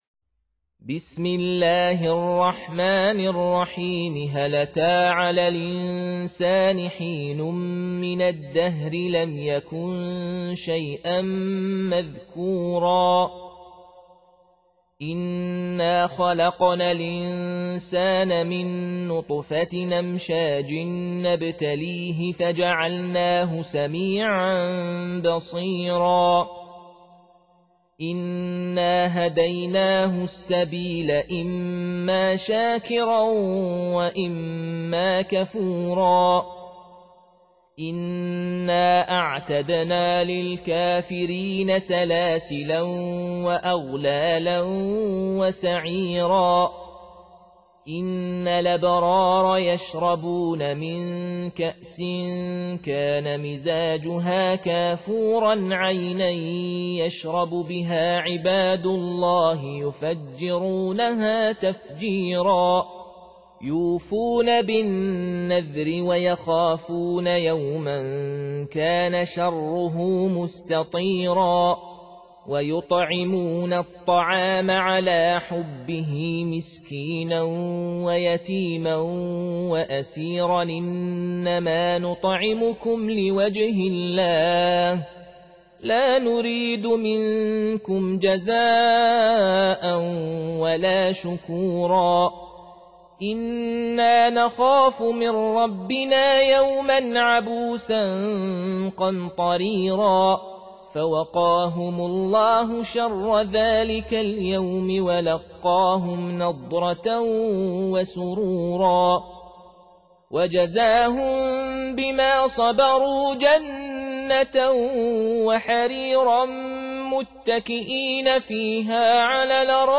Reciting Murattalah Audio for 76. Surah Al-Insân or Ad-Dahr سورة الإنسان N.B *Surah Includes Al-Basmalah